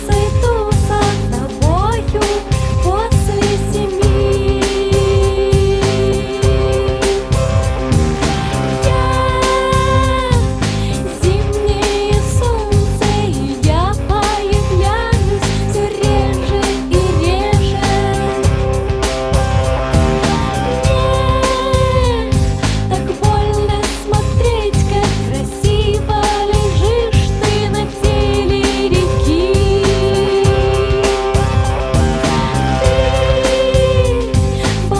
Жанр: Рок / Русские